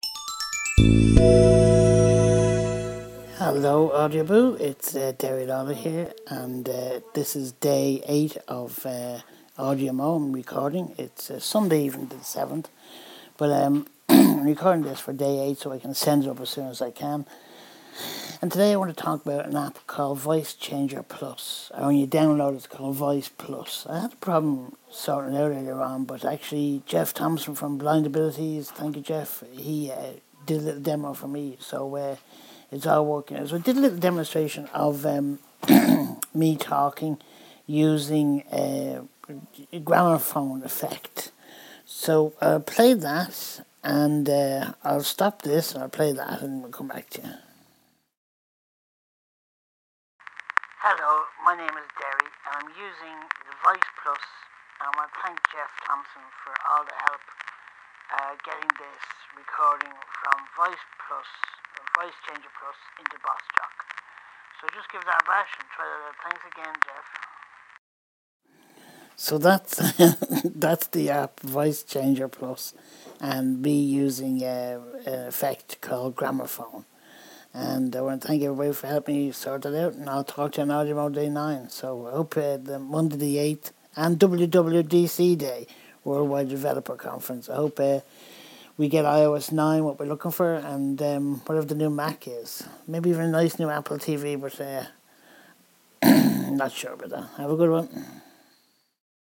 voice changer plus day8#AudioMo